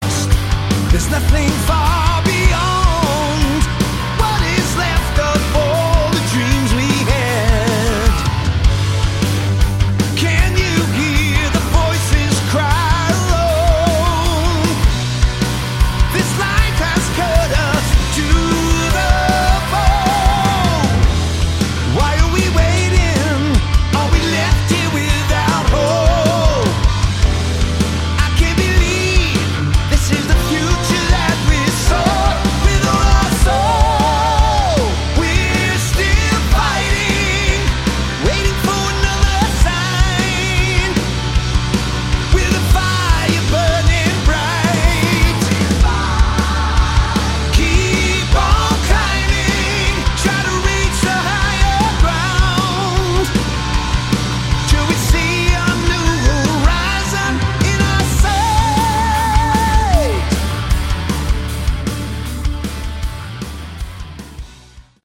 Category: AOR / Melodic Hard Rock
vocals
guitar
keyboards
bass
drums